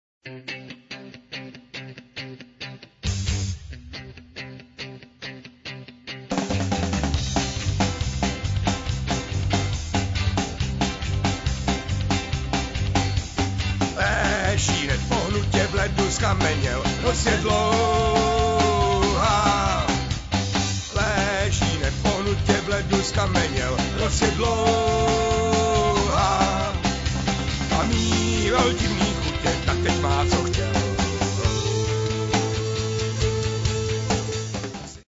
Hospodský rock
cello
voc,sax